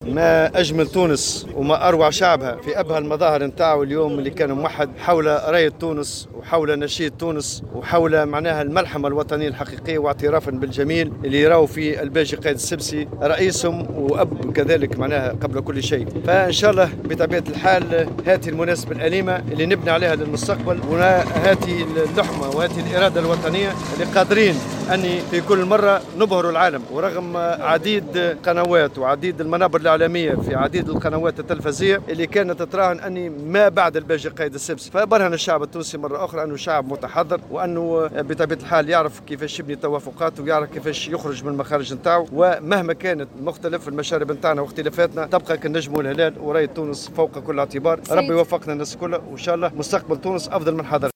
قال الأمين العام للاتّحاد العام التونسي للشّغل، نور الدين الطبوبي، إن شعب تونس كان موحّدا حول الرّاية الوطنية اليوم بمناسبة حدث موكب تشييع جثمان الرئيس الراحل الباجي قايد السبسي. وتابع في تصريح لمراسل "الجوهرة أف أم" ان الشعب التونسي أعلن اليوم اعترافه بالجميل للرئيس الراحل الباجي قايد السبسي وبرهن على انه شعب متحضّر، وفق تعبيره، معربا عن أمله في ان يكون مستقبل تونس أفضل من حاضرها.